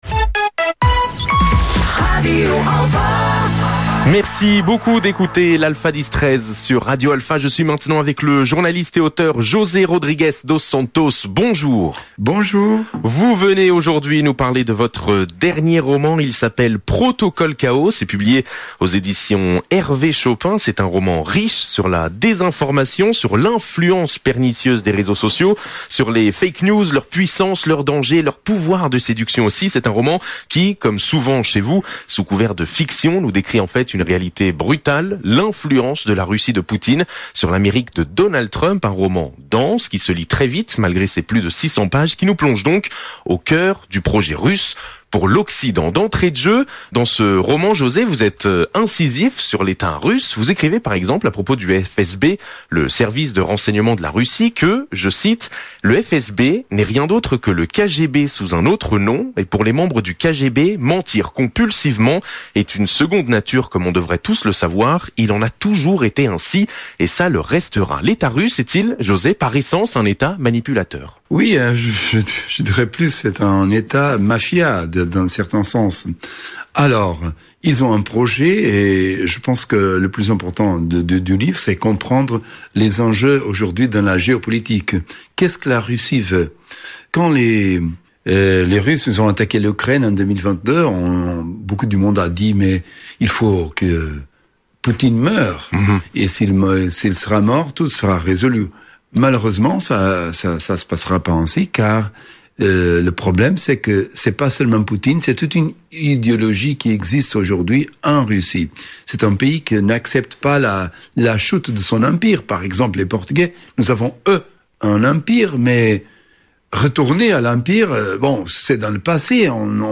José Rodrigues dos Santos dans les studios de Radio Alfa.
Interview-Jose-Rodrigues-dos-Santos.mp3